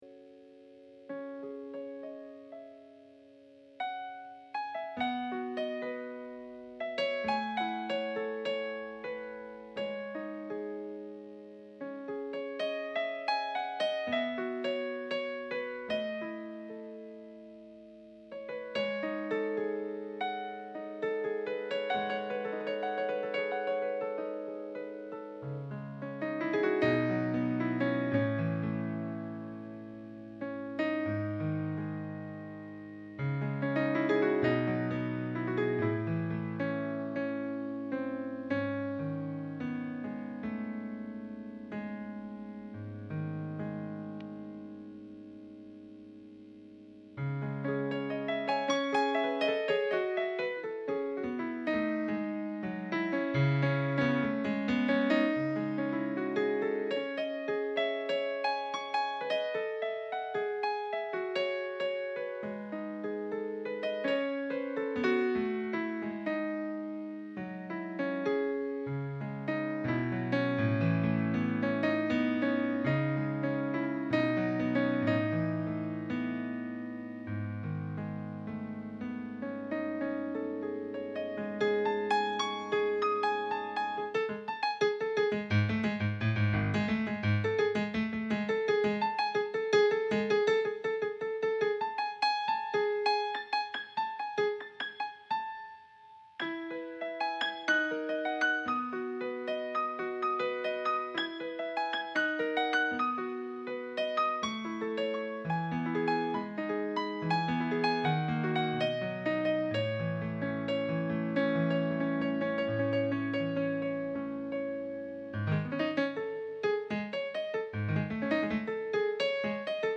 Tonalités de la bémol en ré bémol ou sol dièse en do dièse, majeur ou mineur. Des thèmes connus ou miens s’enchaînant selon l’inspiration du moment. Ma manière d’improviser.
Que cette pièce au piano.